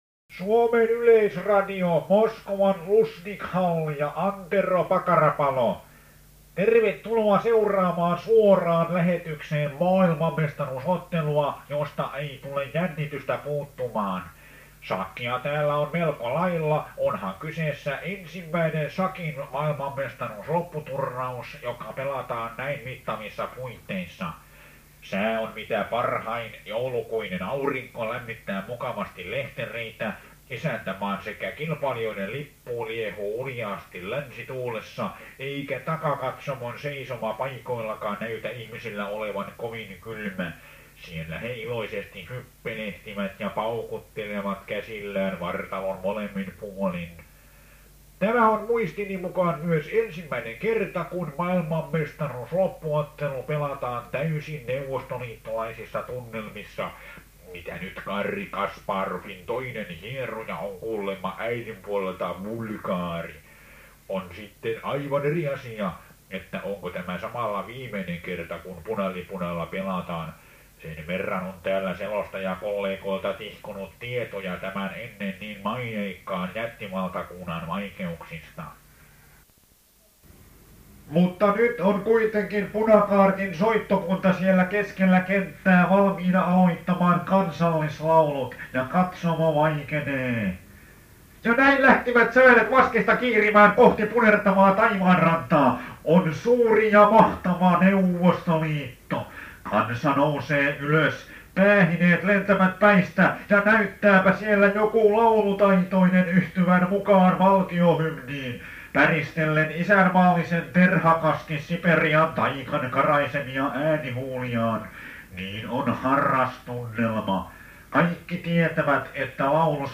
Julkaisukelpoisista kuunnelmista olkoon tässä esimerkkinä Dominante-kuoron v. 1991 Oseanian-matkan piristykseksi syntynyt “Shakkiottelu”.